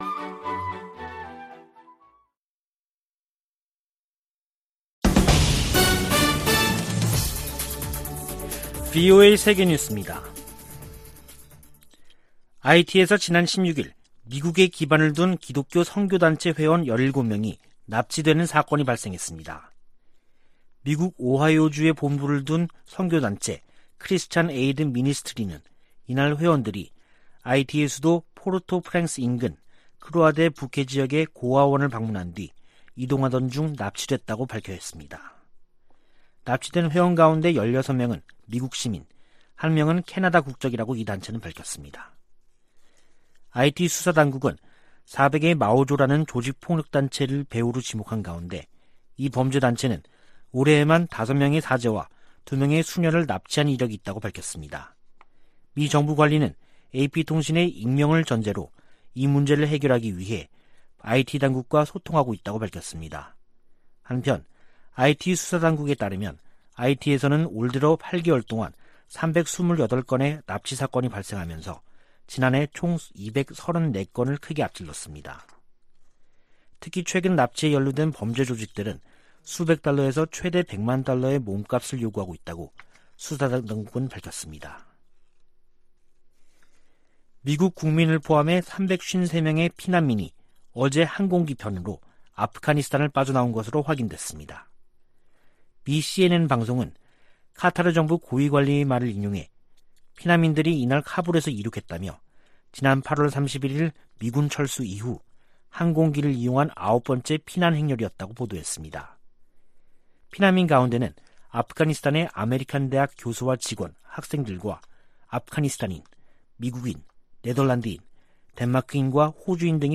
VOA 한국어 간판 뉴스 프로그램 '뉴스 투데이', 2021년 10월 18일 2부 방송입니다. 미군 정보당국이 새 보고서에서 북한이 장거리 미사일 시험 발사와 핵실험을 재개할 수 있다고 전망했습니다. 미 국무부는 한반도의 완전한 비핵화 목표를 진전시키기 위해 한국, 일본과 긴밀한 협력을 지속하고 있다고 밝혔습니다. 이인영 한국 통일부 장관은 보건방역 분야 대북 인도적 협력 방안에 대해 미-한 간 구체적인 진전이 있다고 밝혔습니다.